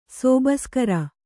♪ sōbaskara